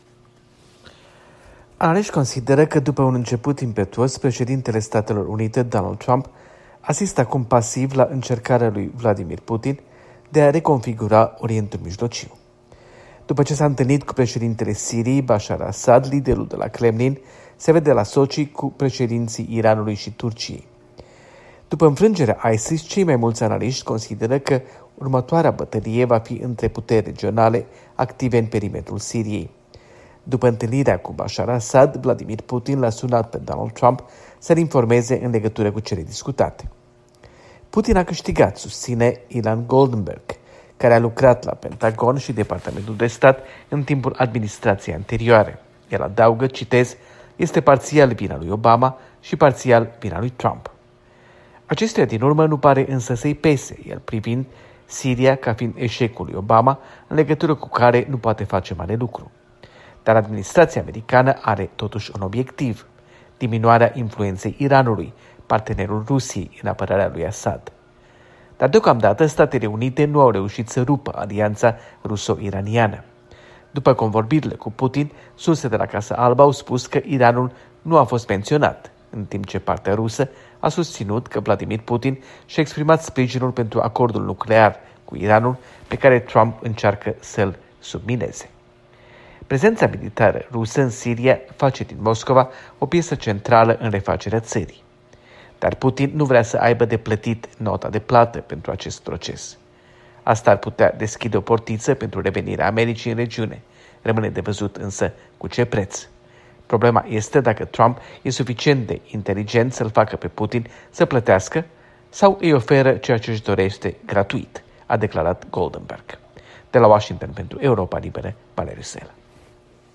Statele Unte si criza din Siria - relatare de la Washington